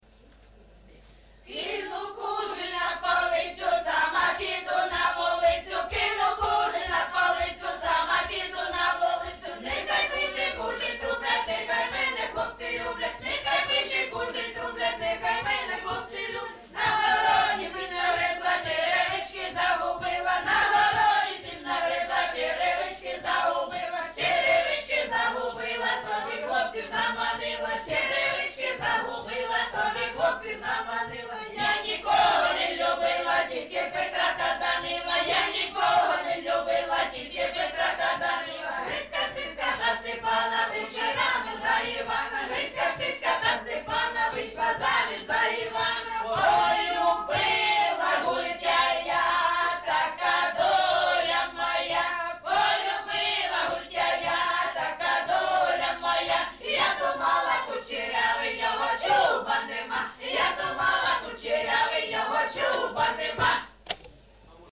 ЖанрЖартівливі
Місце записус-ще Щербинівка, Бахмутський район, Донецька обл., Україна, Слобожанщина